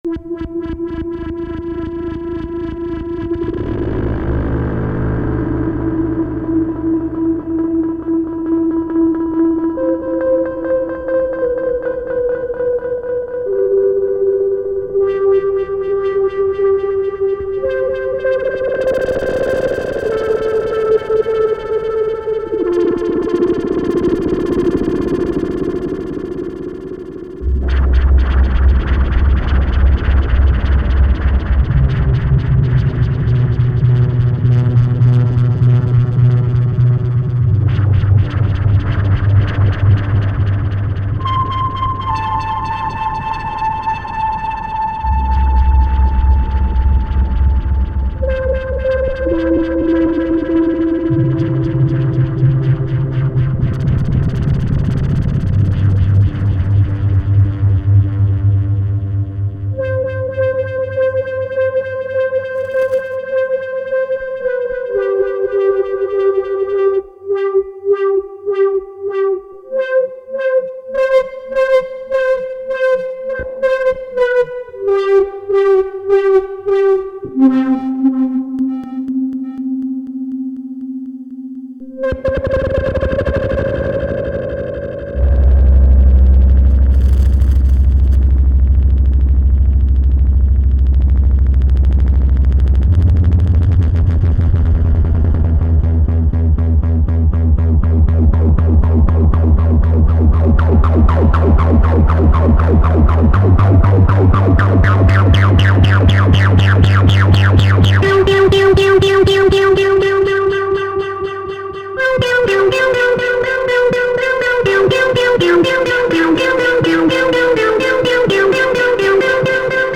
Jam based around a single oscillator, where I manipulate the filter with a looping AR generator. Computer is only there for recording.